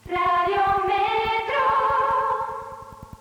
Megafonia